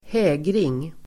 Folkets service: hägring hägring substantiv, mirage Uttal: [²h'ä:gring] Böjningar: hägringen, hägringar Synonymer: synvilla Definition: synvilla, illusion ((optical) illusion) looming substantiv, hägring